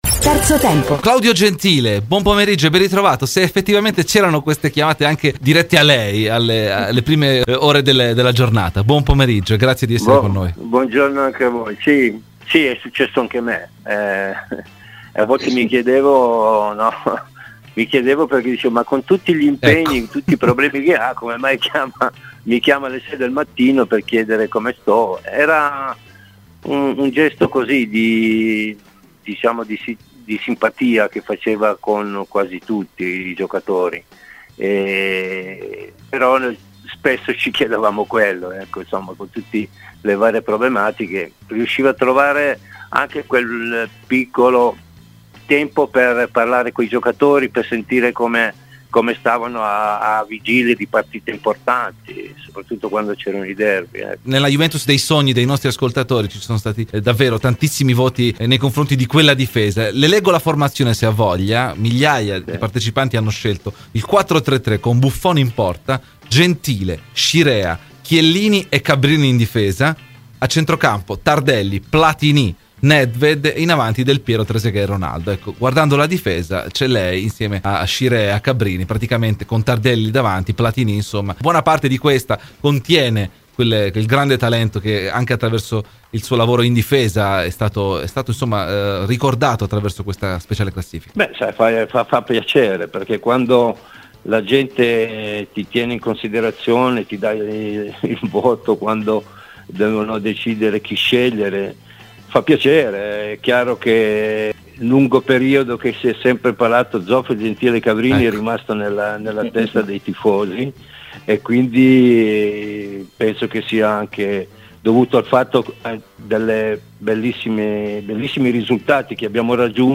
Ai microfoni di Radio Bianconera, nel corso di ‘Terzo Tempo’, è intervenuto l’ex giocatore della Juventus Claudio Gentile: “Se mi rivedo in qualche giocatore in attività?
Claudio Gentile ai microfoni di "Terzo Tempo"